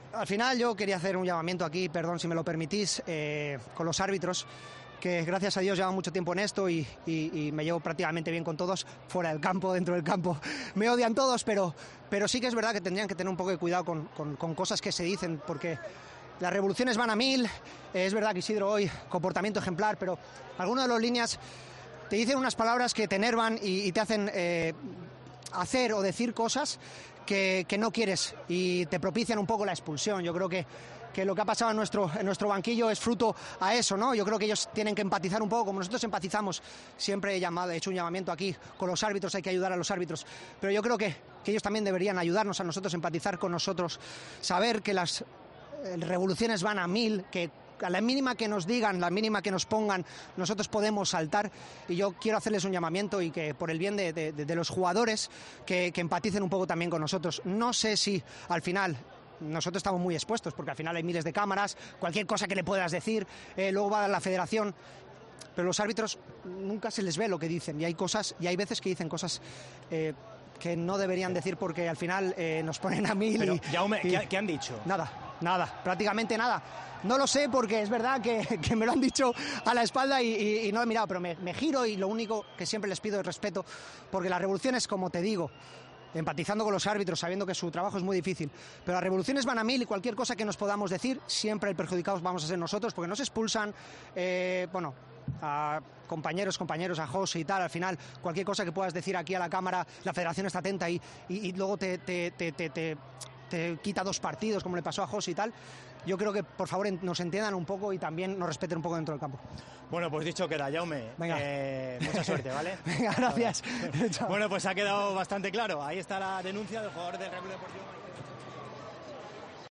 Tras el partido ante el Espanyol y con varias expulsiones de por medio, el jugador del Mallorca explota en zona mixta: "Los árbitros dicen cosas que nos enervan, les pido respeto"